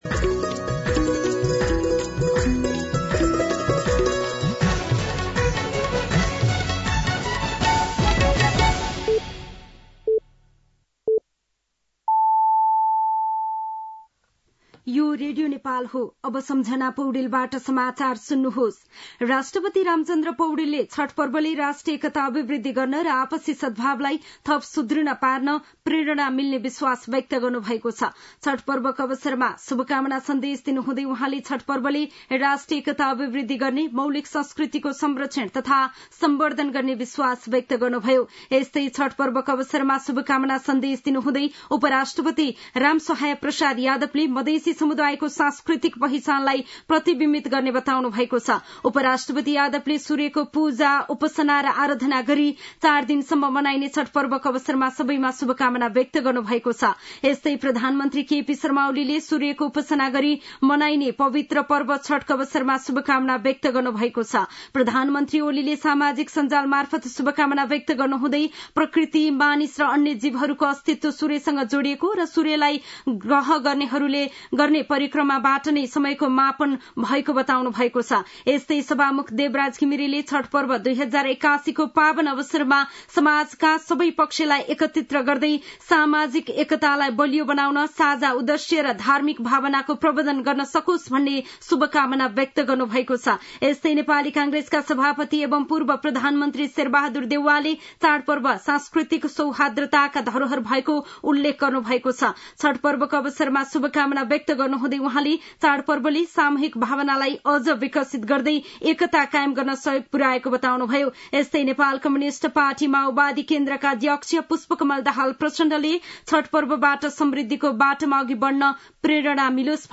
दिउँसो ४ बजेको नेपाली समाचार : २३ कार्तिक , २०८१
4pm-News-07-22.mp3